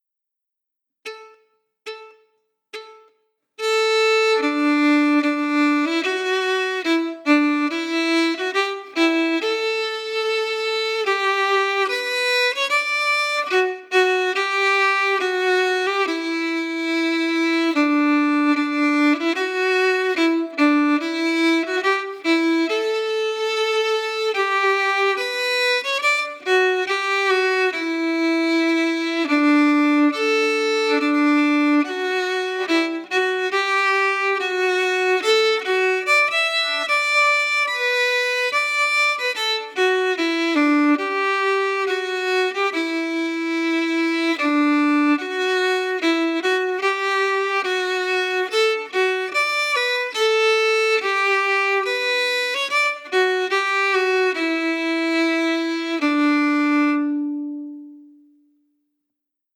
Key: D*
Form: March (marching song)
Played slowly for learning
Genre/Style: Scottish marching song